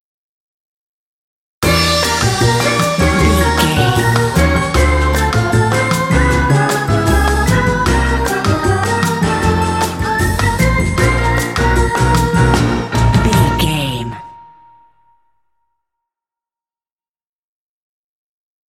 Uplifting
Aeolian/Minor
percussion
flutes
piano
orchestra
double bass
silly
circus
goofy
comical
cheerful
perky
Light hearted
quirky